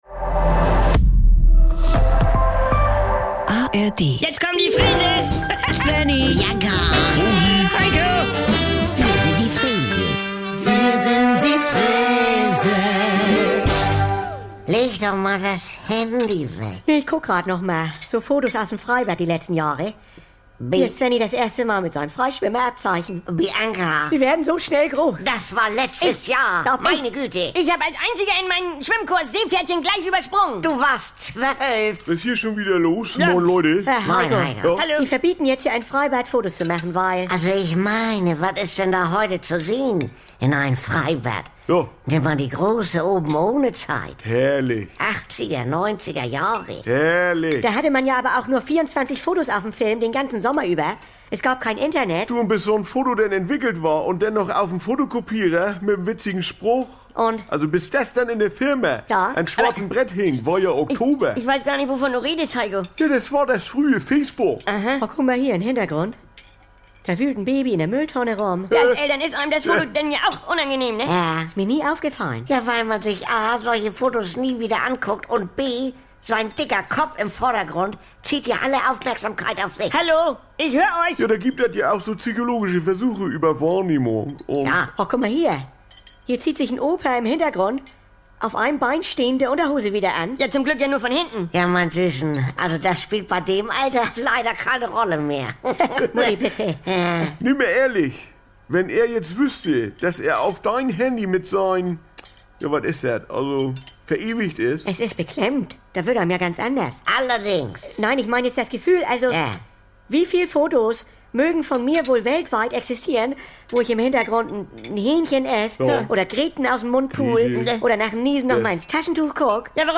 … continue reading 1680 에피소드 # Saubere Komödien # Unterhaltung # NDR 2 # Komödie